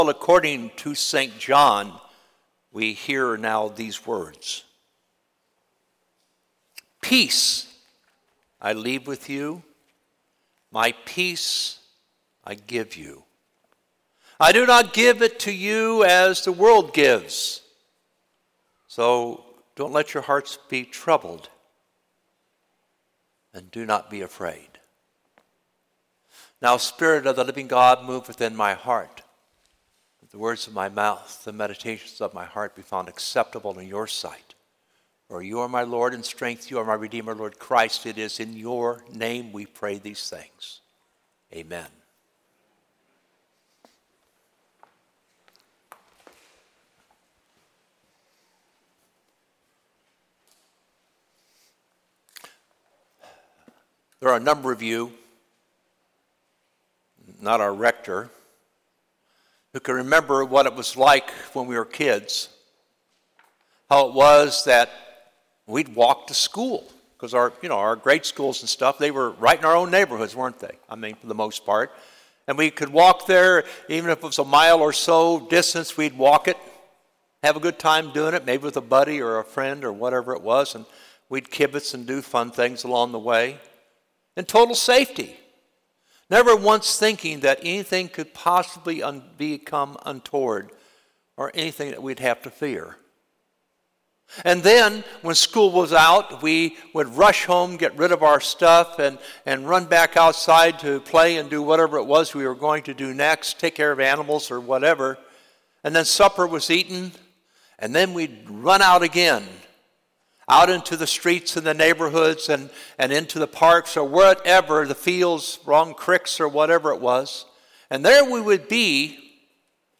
Sermon 7/14/24 Eighth Sunday after Pentecost